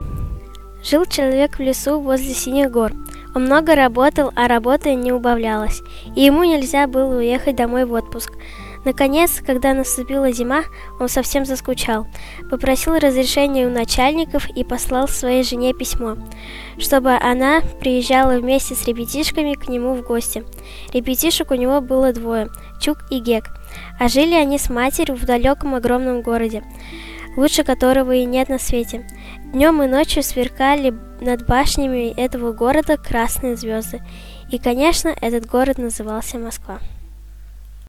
Январский подкаст студии звукозаписи Наумовской библиотеки приурочен к 120-летию со дня рождения детского писателя Аркадия Петровича Гайдара.
А потом вместе записали аудиотреки отрывков из произведений «Чук и Гек», «Голубая чашка», «Горячий камень» и, конечно, «Тимур и его команда».
Rasskaz-CHuk-i-Gek-Arkadij-Gajdar.mp3